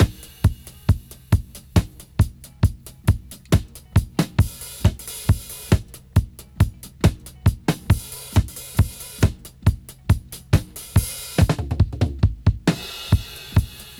136-DRY-04.wav